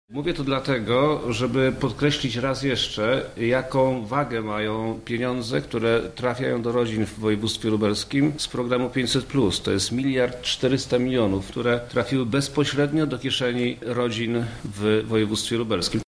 Jak podkreślił, wojewoda Przemysław Czarnek, Lubelskie wciąż znajduje się wśród najbiedniejszych regionów Unii Europejskiej.